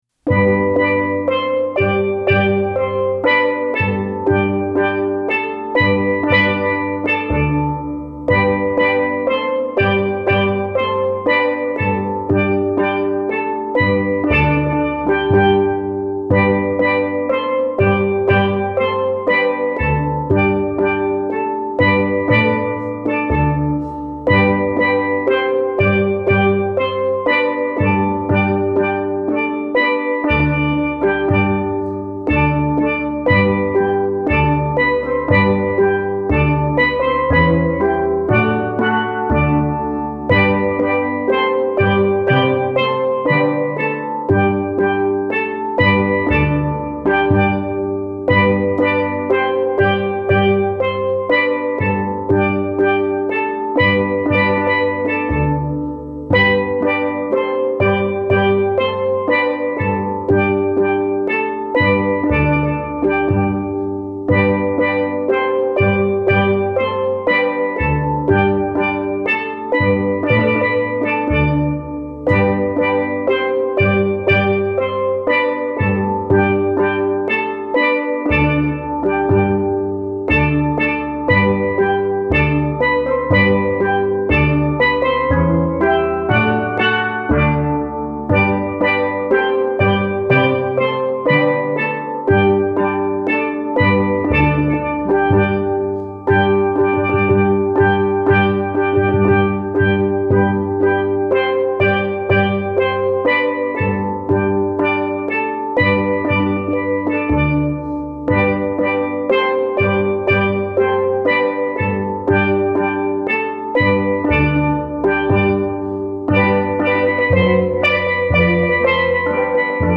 Steel Drum Wedding
selections played on island steel